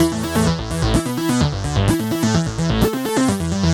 Index of /musicradar/french-house-chillout-samples/128bpm/Instruments
FHC_Arp C_128-A.wav